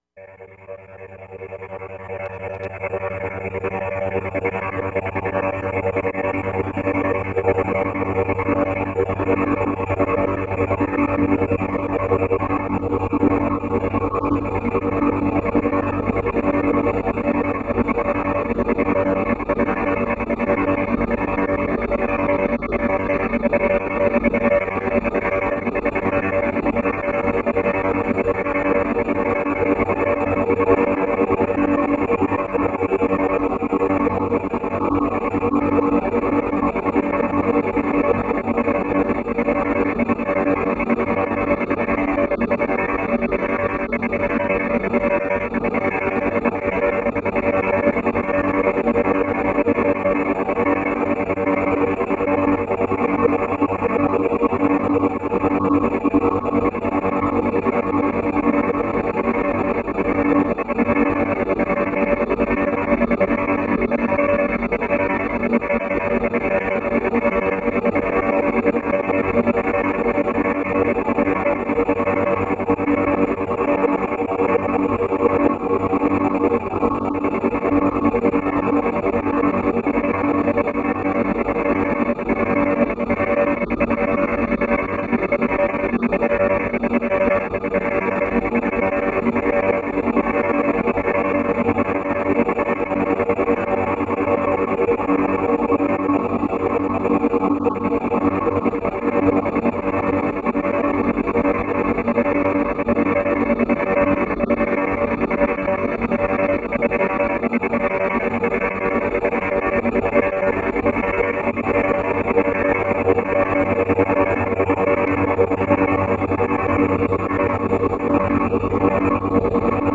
Impulse Response for Busseto Cathedral
Spatialized ch. 1&2
Each track is rotated (CW or CCW) with the automated diffusion control offered in the ABControl software for the AudioBox, to a sequence of output channels at a rapid pace (100, 150 or 200 ms per step), with no cross-fade.